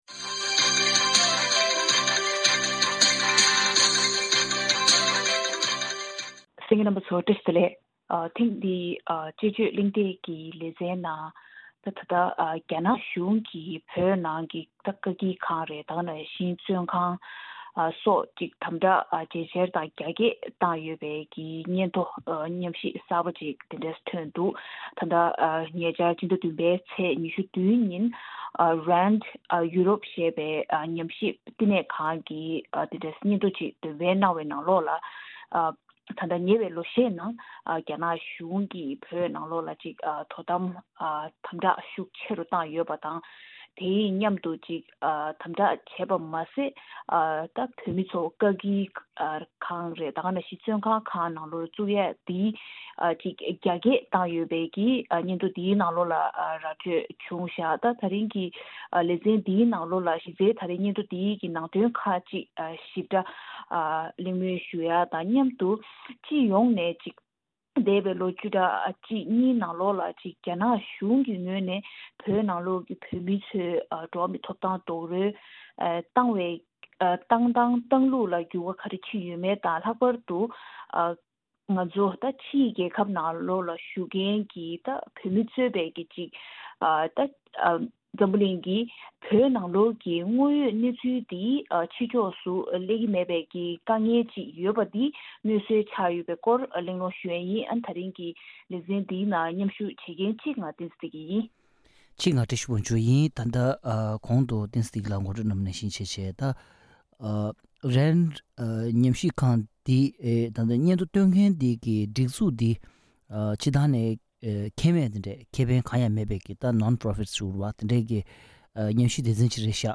དཔྱད་གཞིའི་གླེང་མོལ་ཞུས་པར་གསན་རོགས་གནང་།